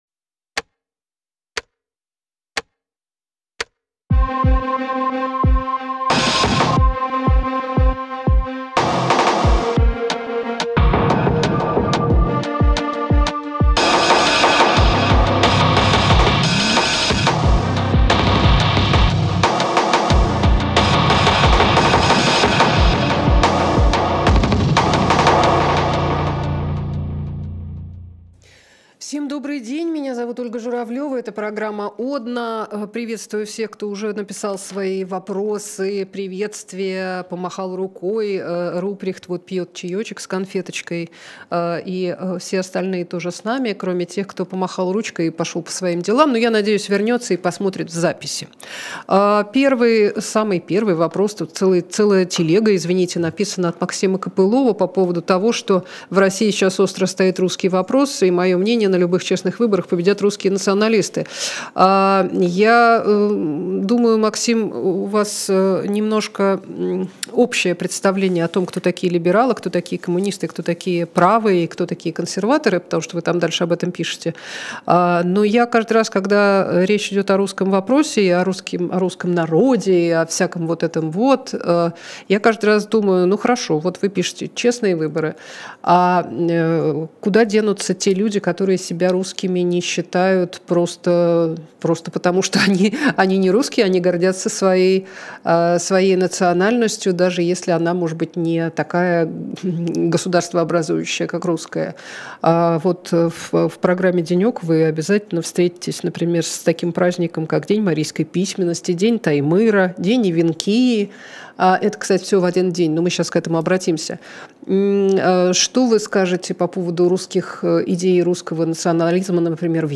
Ольга Журавлёва общается с вами в прямом эфире